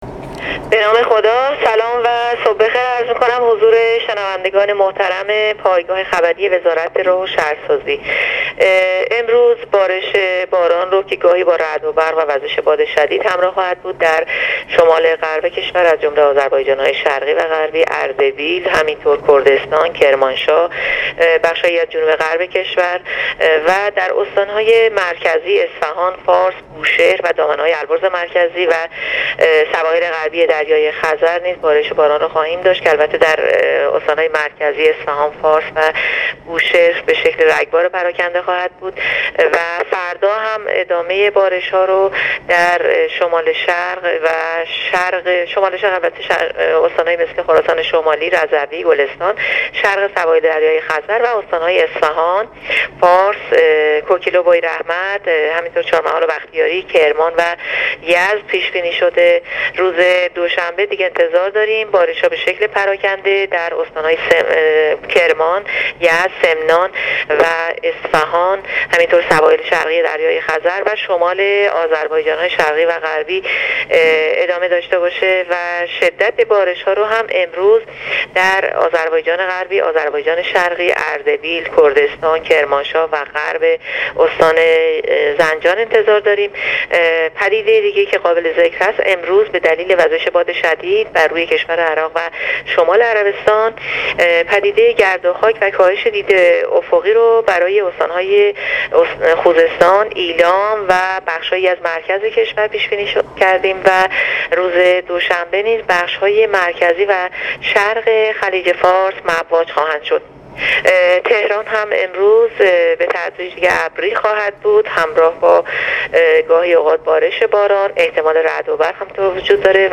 گزارش رادیو اینترنتی وزارت راه و شهرسازی از آخرین وضعیت آب و هوا ۱۴ اردیبهشت۹۸/بارش باران در شمال غرب، بخش هایی از جنوب غرب و استان های مرکزی /ورود سامانه بارشی جدید از غرب از شنبه